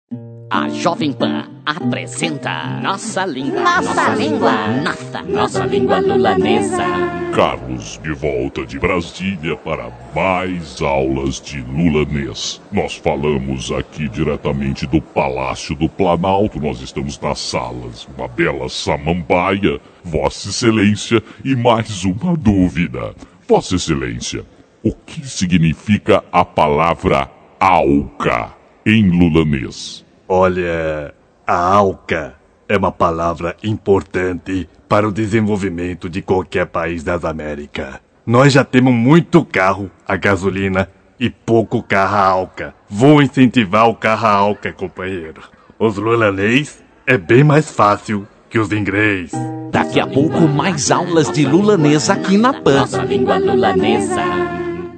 Quadro Lulanês (idioma falado por Lula) da Jovem Pan de 2002.